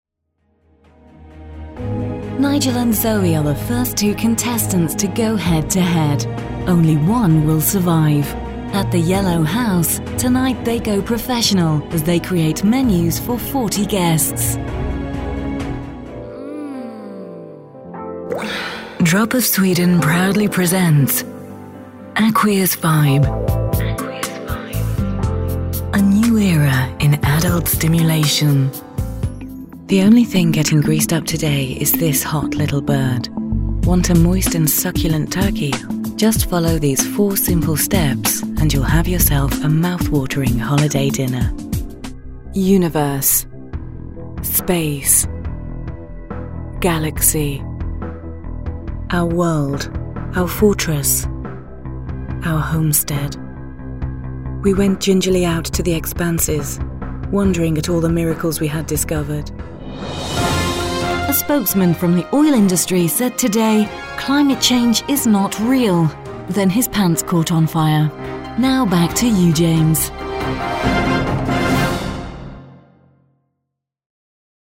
Sprechprobe: Industrie (Muttersprache):
Experienced versatile British female voice over with home studio. Warm, soothing and luxurious through to fun, bright and bubbly.